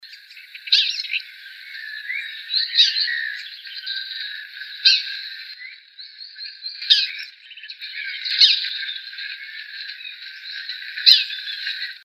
Cardenilla (Paroaria capitata)
Nombre en inglés: Yellow-billed Cardinal
Fase de la vida: Adulto
Localidad o área protegida: Reserva Ecológica Costanera Sur (RECS)
Condición: Silvestre
Certeza: Vocalización Grabada